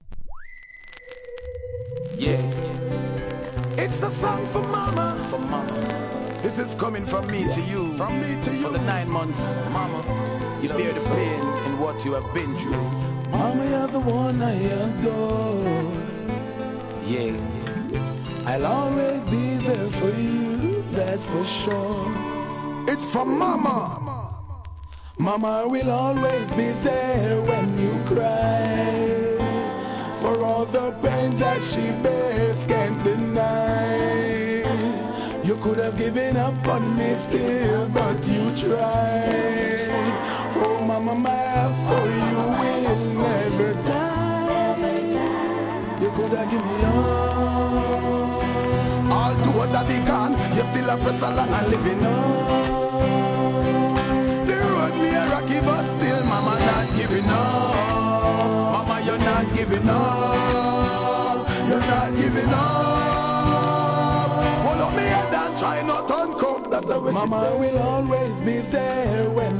REGGAE
2006年、アコースティックのGOODミディアム！！